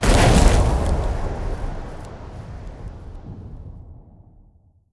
Fly.wav